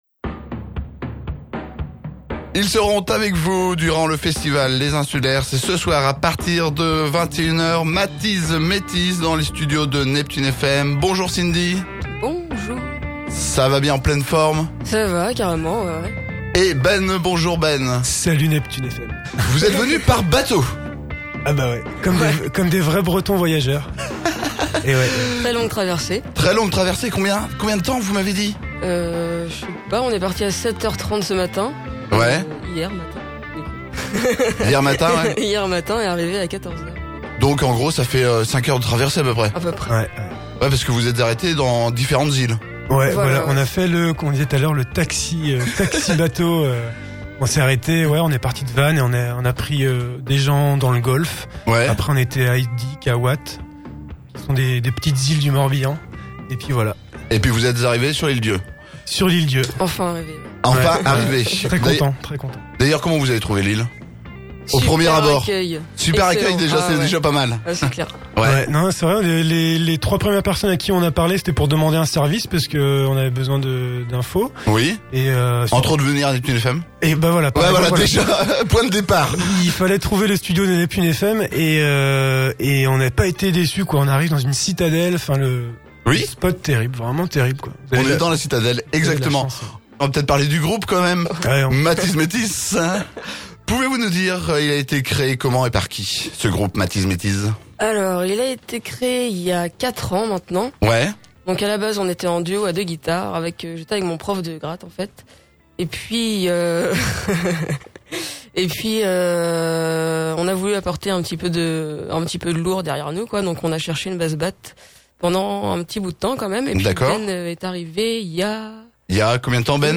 Interview latino